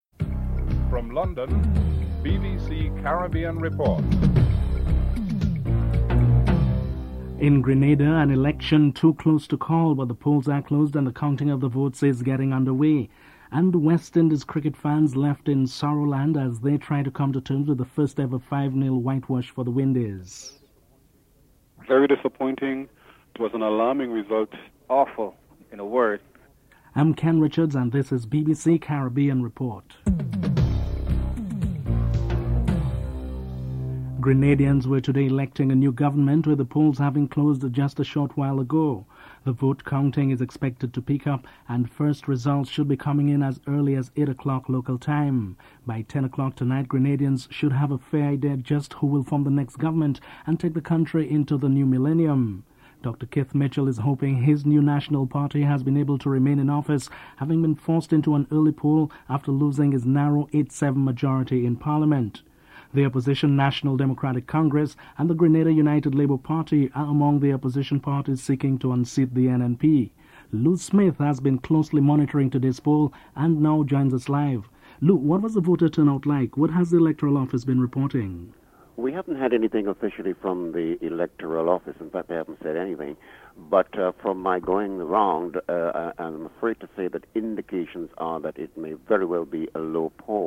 1. Headlines with anchor